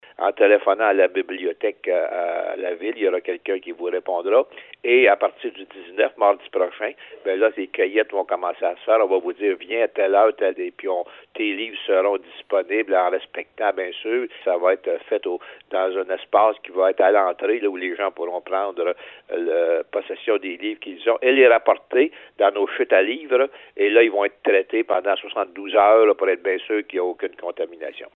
Le maire de Bécancour, Jean-Guy Dubois donne plus de détails :